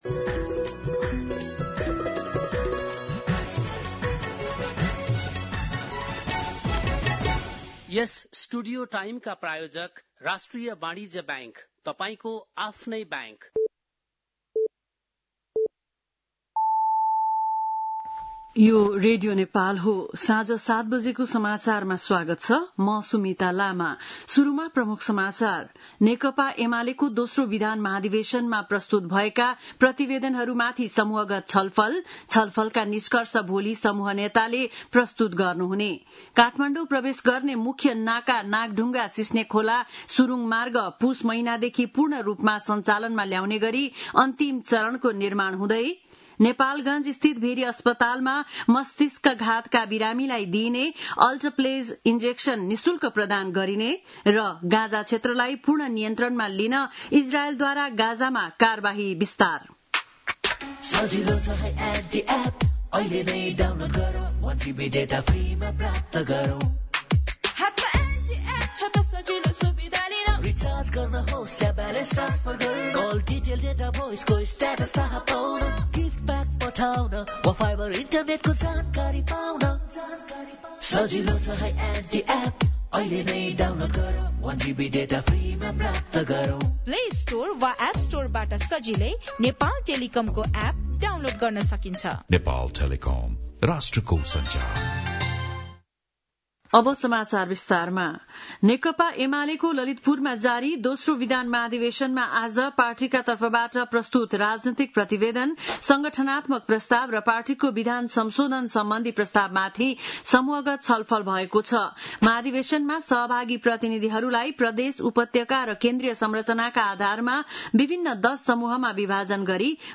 बेलुकी ७ बजेको नेपाली समाचार : २१ भदौ , २०८२